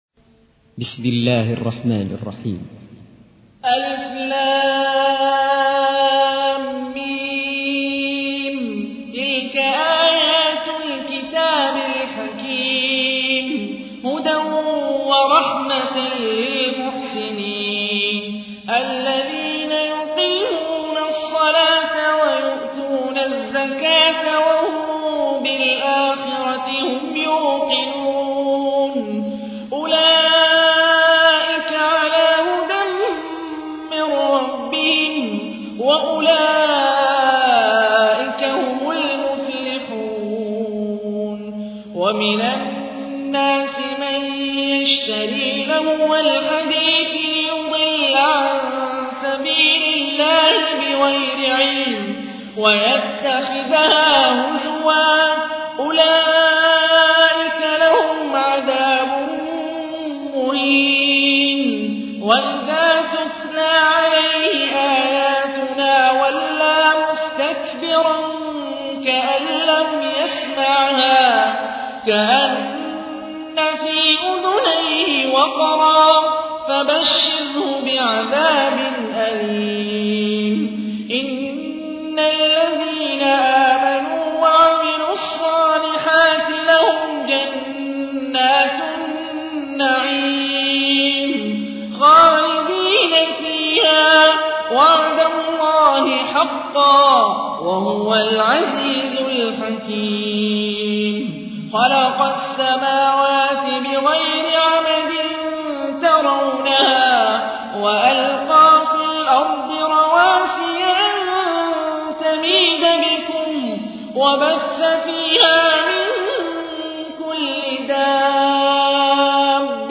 تحميل : 31. سورة لقمان / القارئ هاني الرفاعي / القرآن الكريم / موقع يا حسين